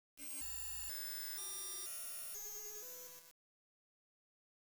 mysterious.wav